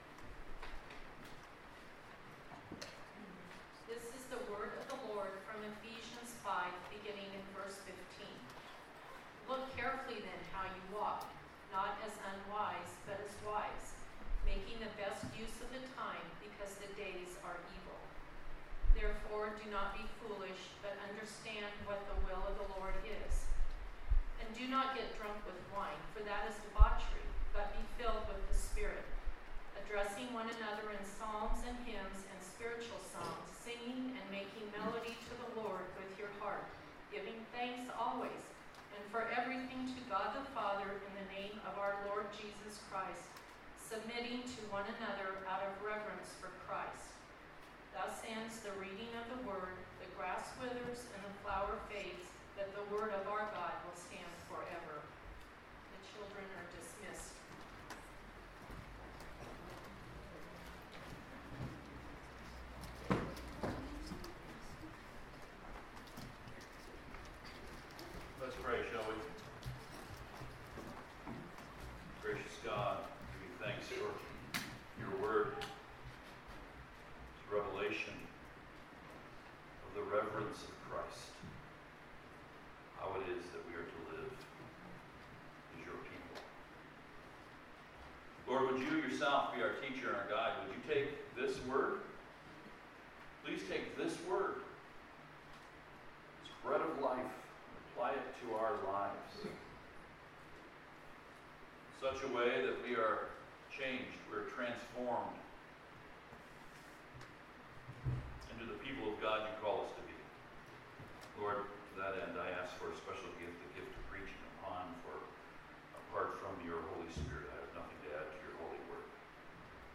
Service Type: Sunday Morning Topics: addressing one another with psalms , walking with the Spirit « Commitment to Christ’s Church Our Refuge